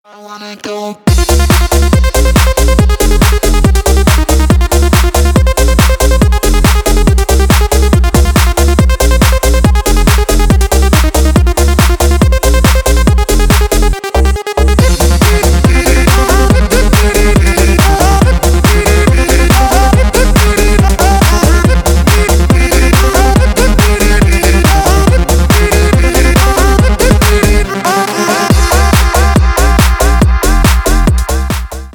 Ритмичная dance нарезка
• Песня: Рингтон, нарезка